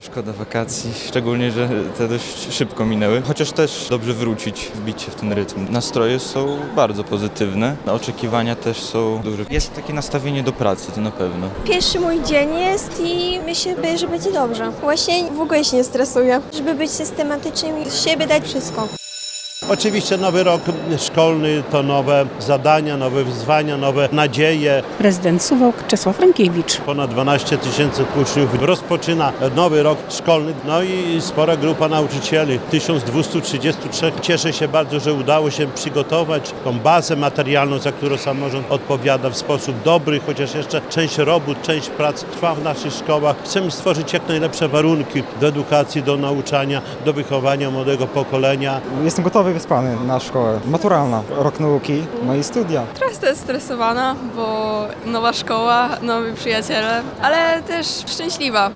Miejskie rozpoczęcie nowego roku szkolnego w Suwałkach - relacja